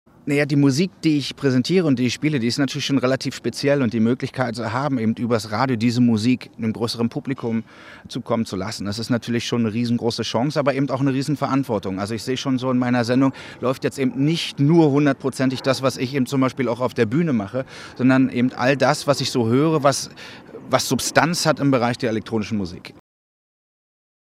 Paul van Dyk erklärt, warum er mit seiner Show "VONYC Sessions" im Radio auftritt. (Interview